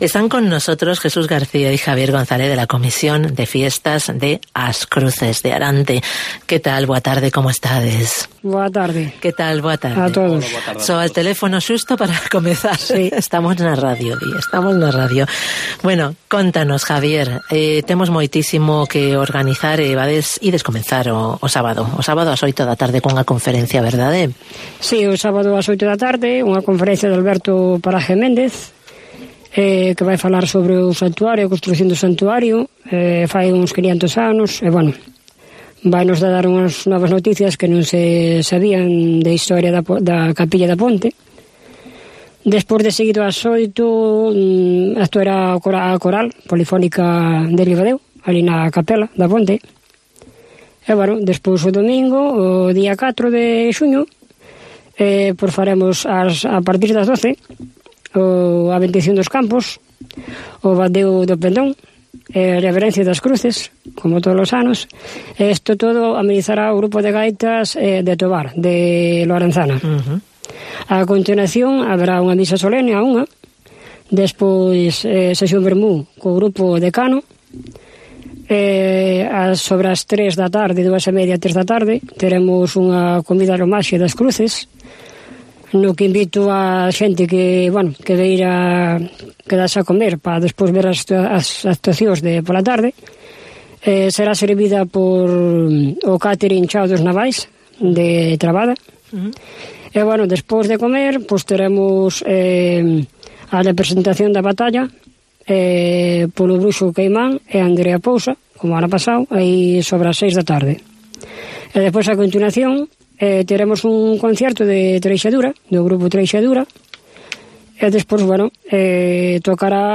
Entrevista con la Comisión de Fiestas de As Cruces de Arante - A Ponte en Ribadeo